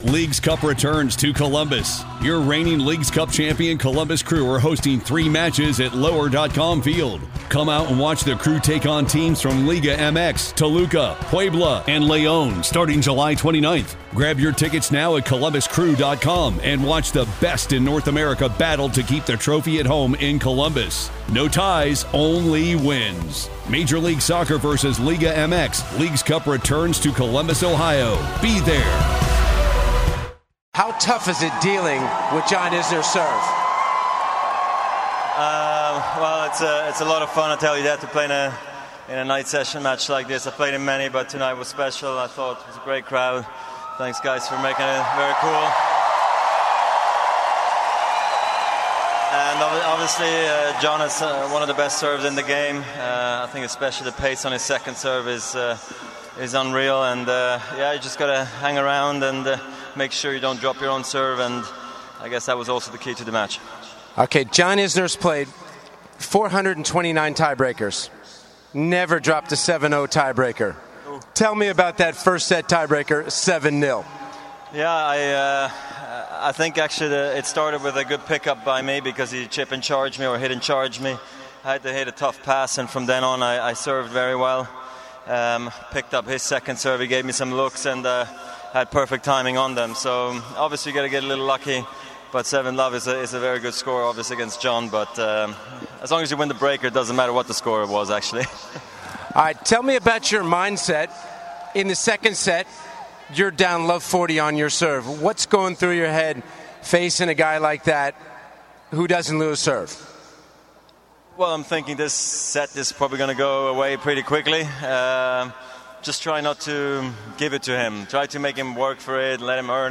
Rodger Federer speaks about his victory over John Isner.